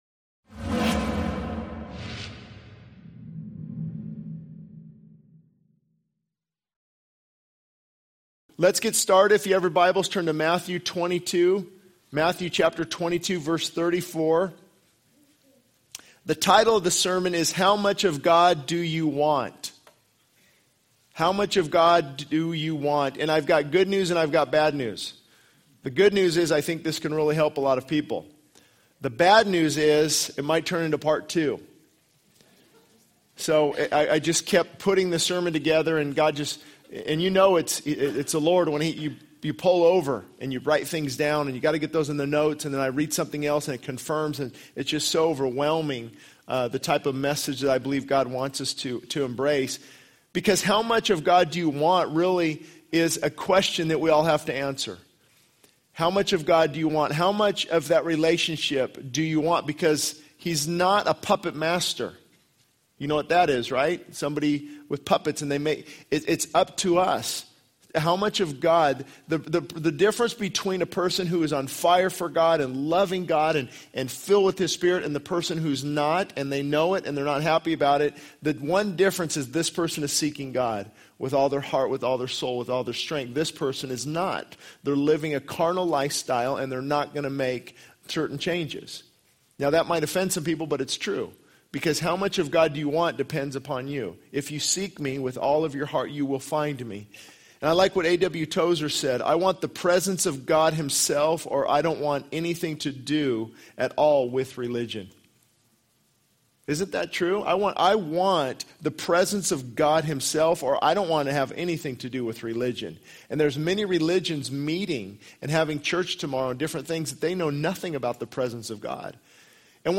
This sermon challenges listeners to consider how much of God they truly want in their lives. It emphasizes the importance of seeking God with all aspects of one's being - heart, mind, and soul. The sermon highlights the need for humility, hunger for healthy spiritual food, and a desire for heaven.